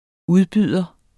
Udtale [ ˈuðˌbyˀðʌ ]